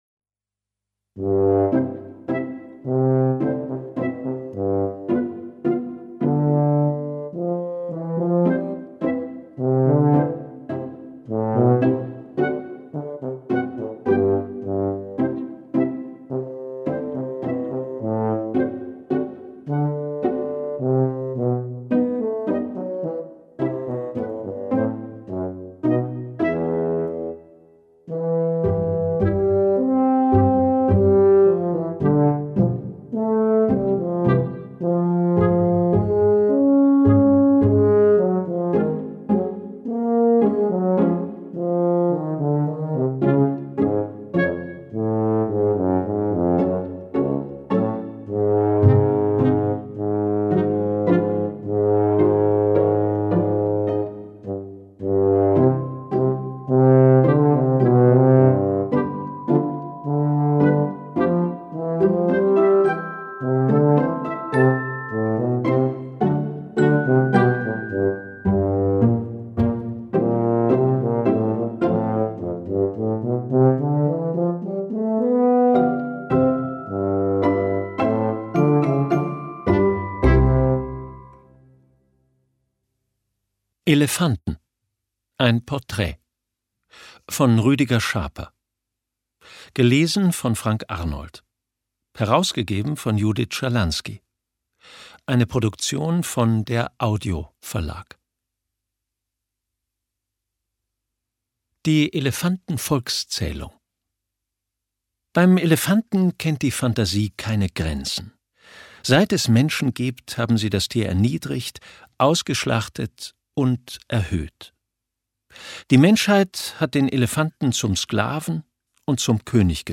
Hörbuch: Elefanten.